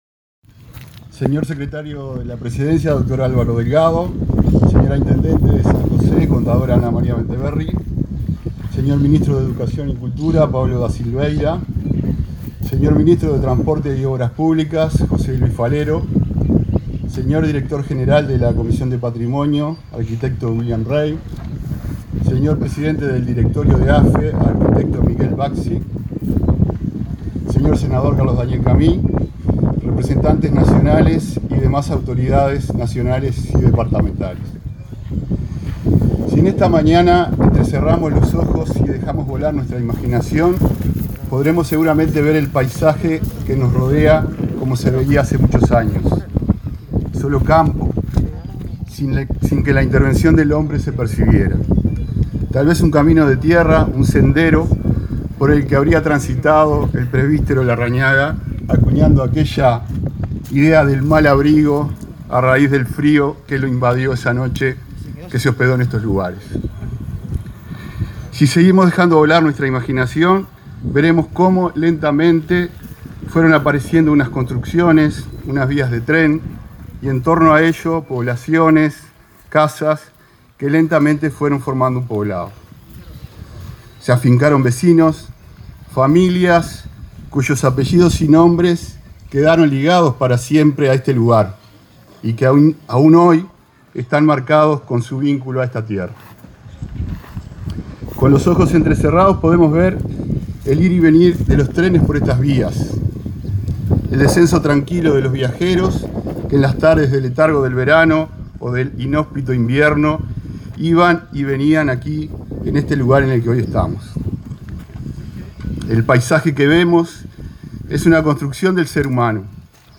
Conferencia de prensa por la declaración de la Estación de Mal Abrigo como Monumento Histórico Nacional
Conferencia de prensa por la declaración de la Estación de Mal Abrigo como Monumento Histórico Nacional 21/08/2021 Compartir Facebook X Copiar enlace WhatsApp LinkedIn Con la asistencia del secretario de Presidencia, Álvaro Delgado; el ministro de Educación y Cultura, Pablo da Silveira; la intendenta de San José, Ana Bentaberri, y el director general de Cultura de San José, Pablo Pucheu, se realizó el acto de declaración de la estación Mal Abrigo como Monumento Histórico Nacional.